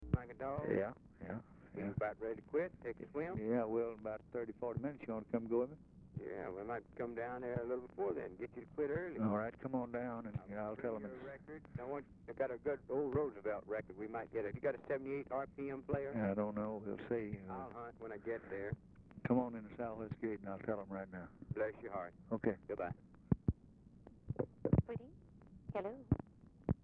Telephone conversation # 1366, sound recording, LBJ and JACK BROOKS, 1/15/1964, 5:50PM | Discover LBJ
Format Dictation belt
Location Of Speaker 1 Oval Office or unknown location